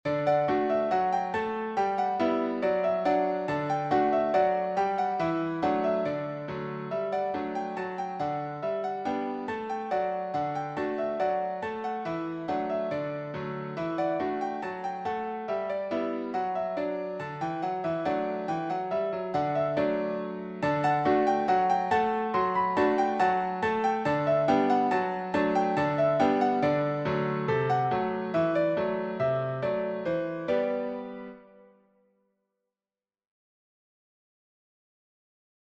Cheerful
Classic ragtime
Piano only